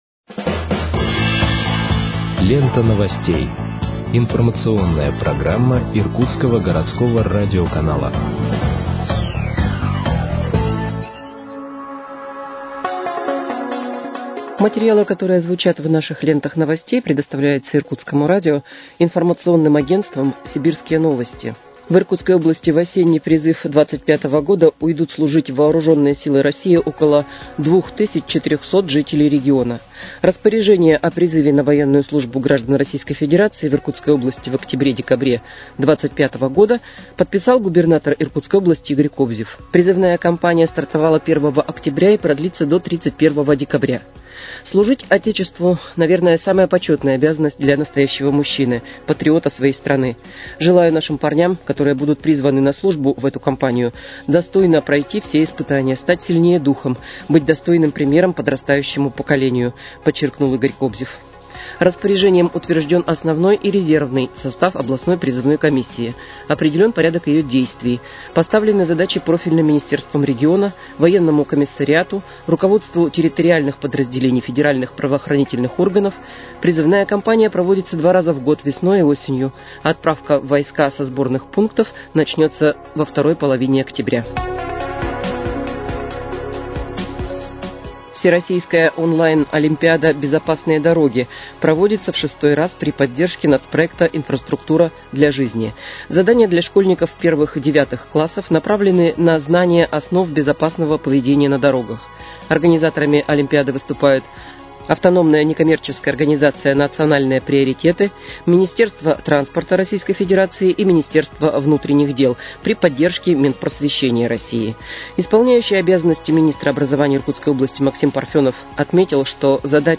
Выпуск новостей в подкастах газеты «Иркутск» от 07.10.2025 № 1